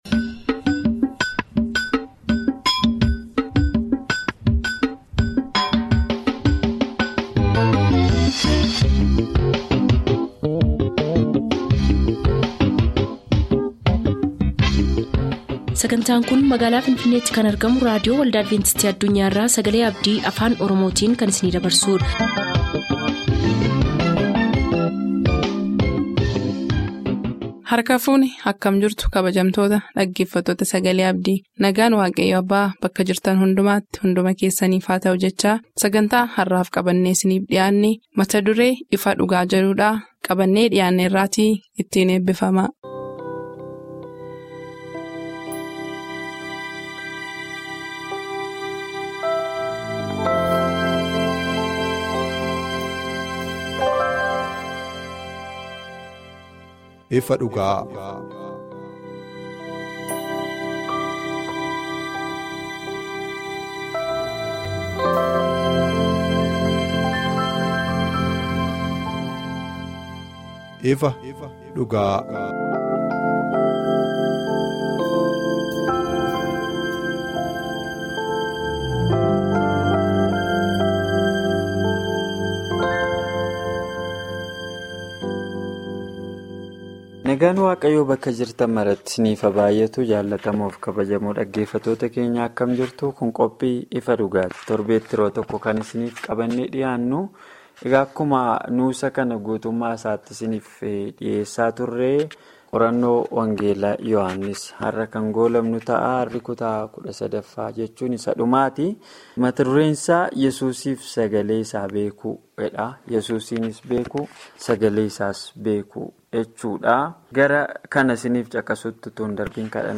Daily Oromifaa radio programs from Adventist World Radio for Ethiopia, Kenya & Somalia